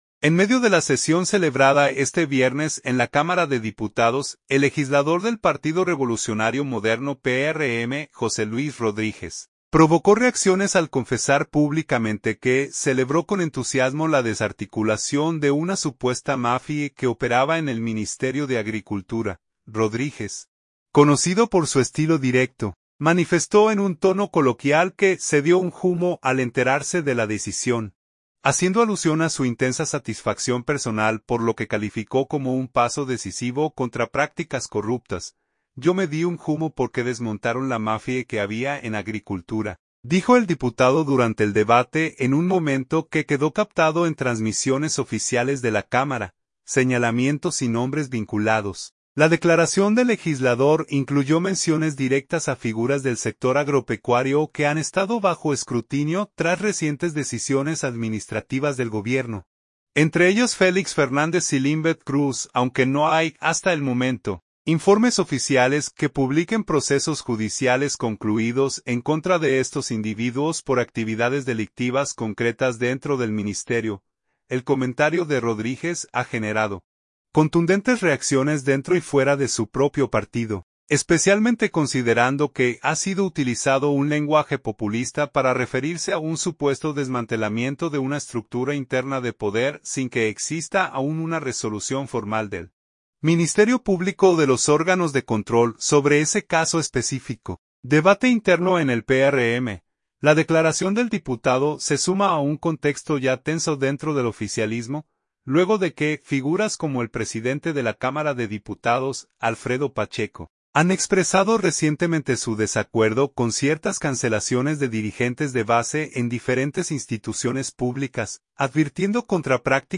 Rodríguez, conocido por su estilo directo, manifestó en un tono coloquial que “se dio un jumo” al enterarse de la decisión, haciendo alusión a su intensa satisfacción personal por lo que calificó como un paso decisivo contra prácticas corruptas.
«Yo me di un jumo porque desmontaron la mafia que había en Agricultura…», dijo el diputado durante el debate, en un momento que quedó captado en transmisiones oficiales de la cámara.